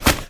Footstep1.wav